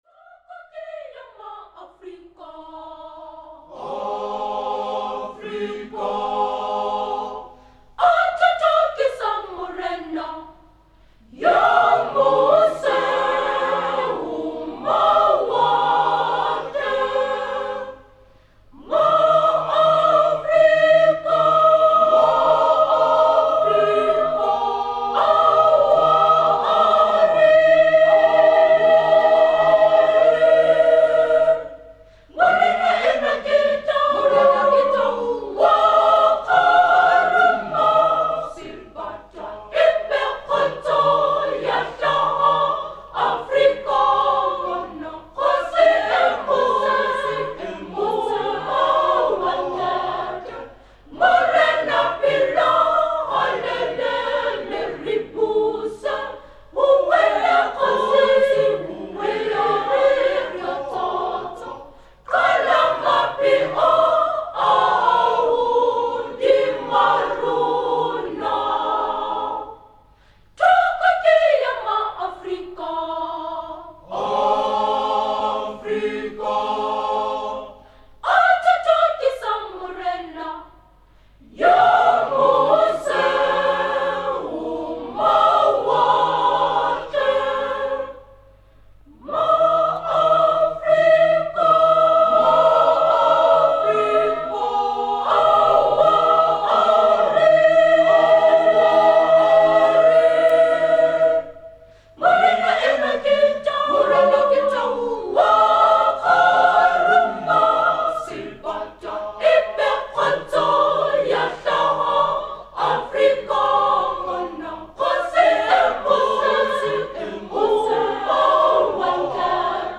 In 1939 Basotoland (now Lesotho) was a British Protectorate under King George VI, here equated with an African Paramount Chief through music of sudden changes and high vocal registers, recalling traditional song, that praises him.
Type: Studio Recording Performers: Sharpeville Methodist Church Choir.
13-thoko-ea-maafrika-praise-song-for-africans.mp3